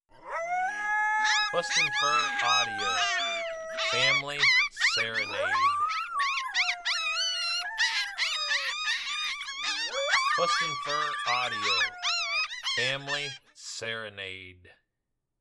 Adult male coyote and 1 month old coyote pups all howling together.